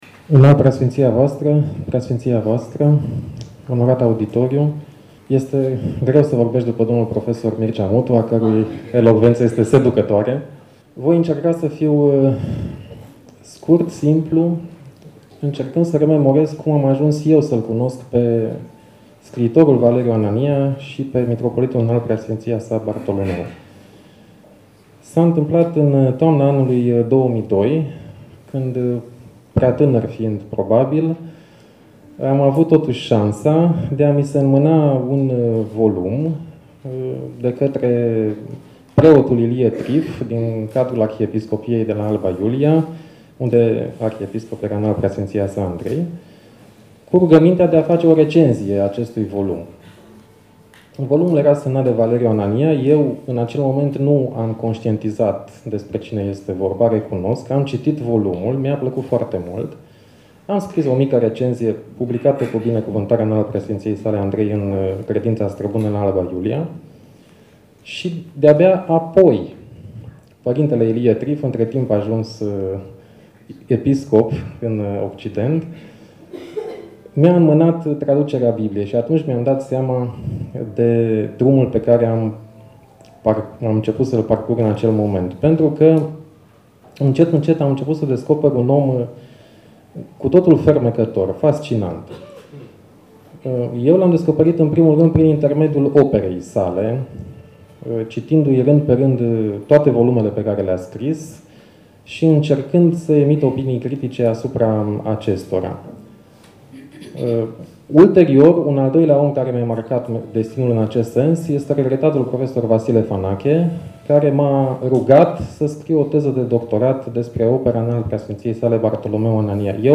Evenimentul s-a desfășurat la Muzeul Mitropoliei Clujului, în seara zilei de 31 ianuarie 2017, începând astfel serile culturale care se vor desfășura și în acest an la muzeul de la demisolul Catedralei.